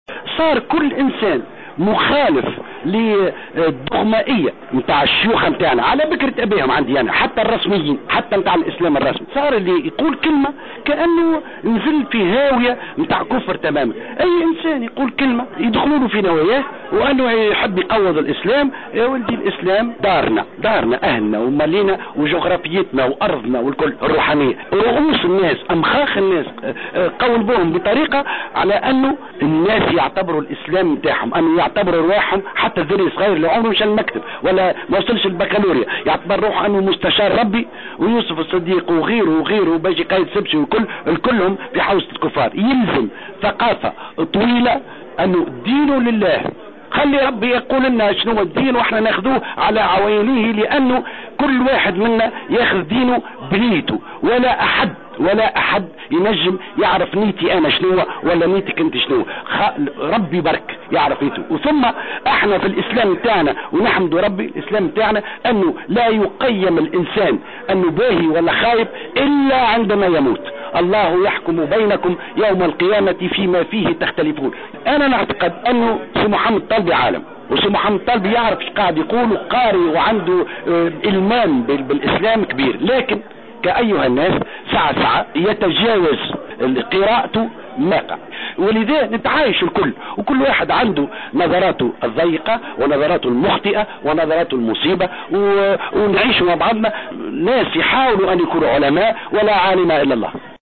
انتقد المفكر الإسلامي يوسف الصديق في حوار مع إذاعة الجوهرة أف أم اليوم الاحد على هامش مشاركته نشاط ثقافي في المهدية، انتقد ما وصفه باستئثار بعض الأطراف بالدين الإسلامي واعتباره ملكا لهم وتكفيرهم لكل مفكر أو باحث أو من يقدم تحليلا للإسلام على غرار يوسف الصديق ومحمد الطالبي وغيره.